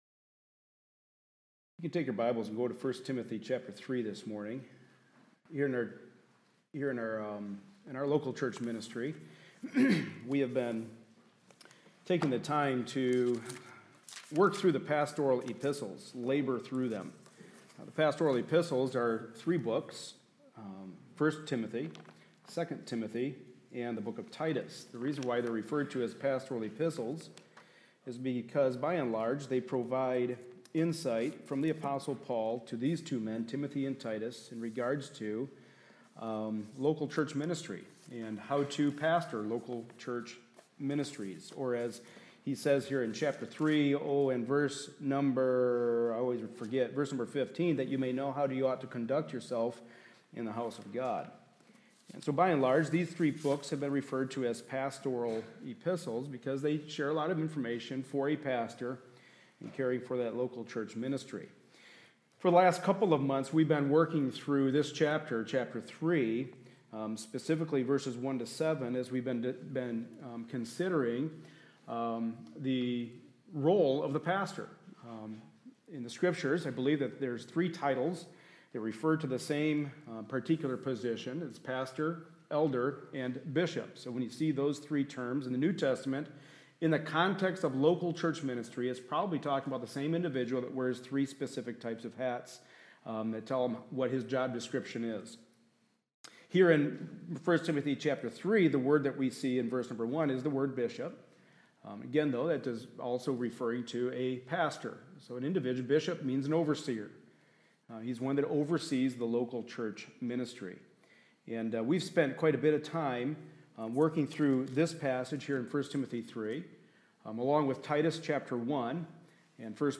1 Timothy 3:1-7 Service Type: Sunday Morning Service A study in the pastoral epistles.